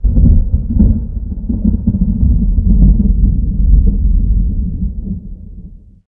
thunder39.ogg